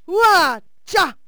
valkyrie_attack2.wav